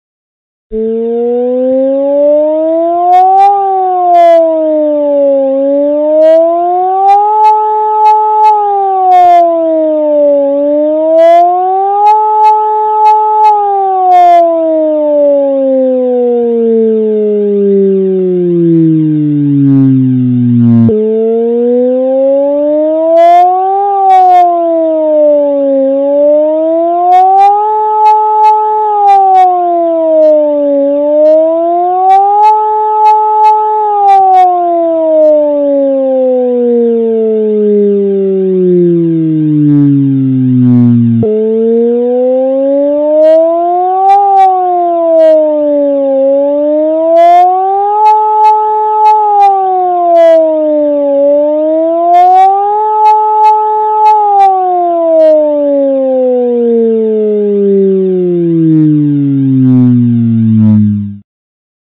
La Belgique compte 570 sirènes d’alerte (
sirene.mp3